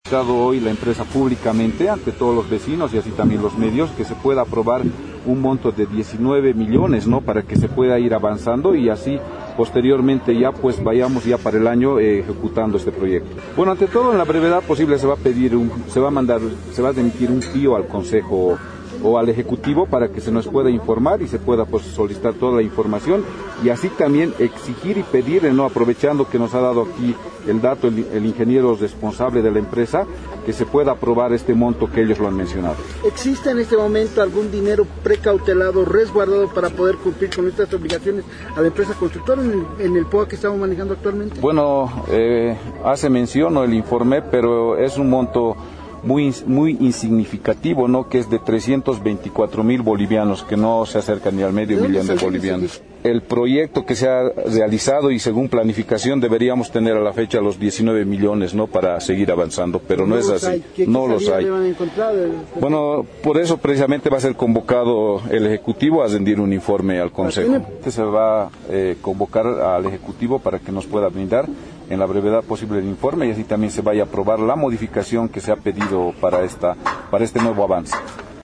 El legislativo municipal convoco a las autoridades de infraestructura y obras para que brinde un informe en relación al avance de la obra, sin embargo, los funcionarios del ejecutivo municipal no se hicieron presente, la obra tiene a la fecha un avance físico del 60 % y se debe destinar por lo menos 19 millones de bolivianos para su conclusión informó Marcelo Fernández, presidente del concejo municipal.